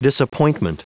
Prononciation du mot disappointment en anglais (fichier audio)
Prononciation du mot : disappointment